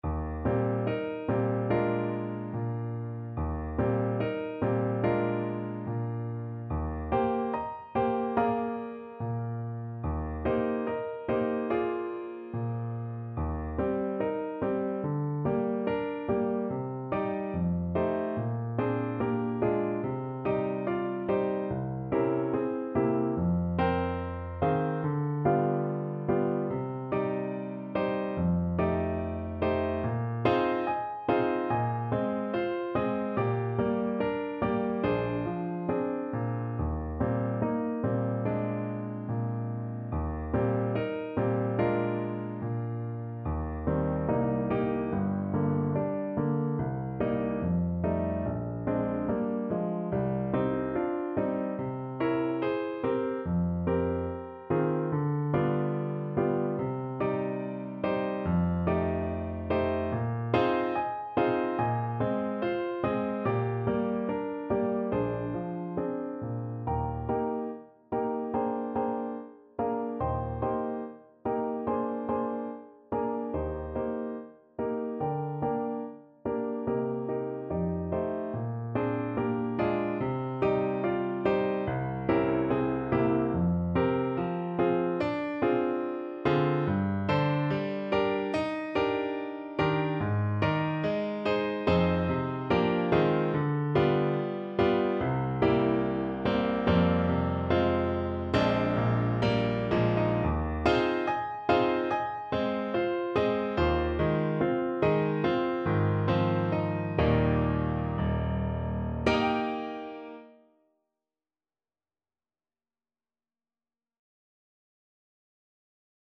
~ = 72 In moderate time
4/4 (View more 4/4 Music)
Classical (View more Classical Trombone Music)